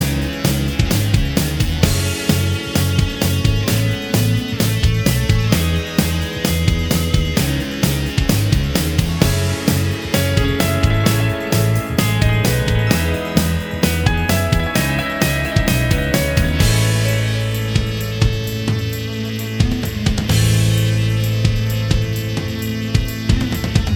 Minus All Guitars Indie / Alternative 4:07 Buy £1.50